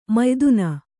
♪ mayduna